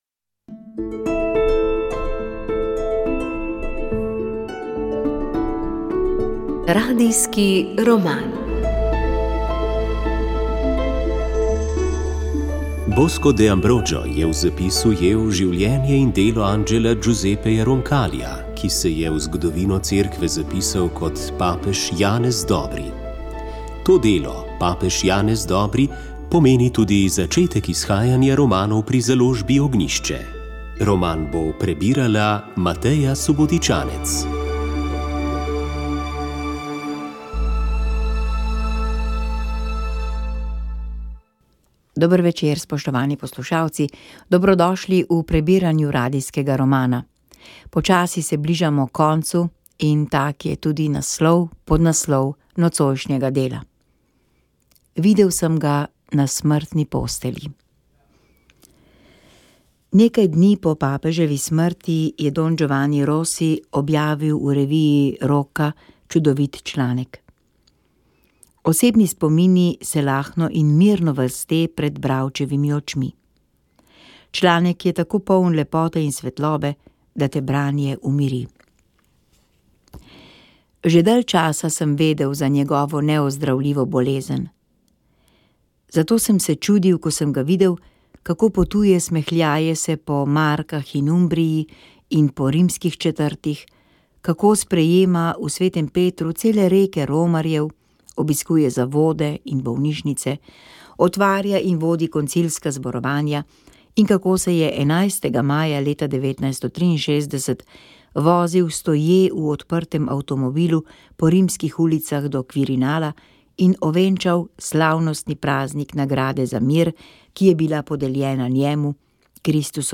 Radio Ognjišče knjiga papež Radijski roman VEČ ...